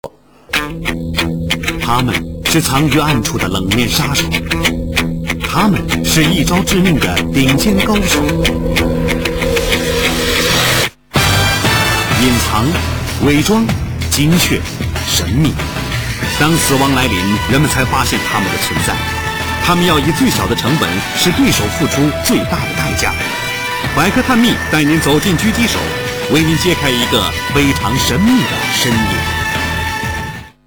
这吉他曲实在是动感十足，就是不知道叫什么名字，都听听（截取）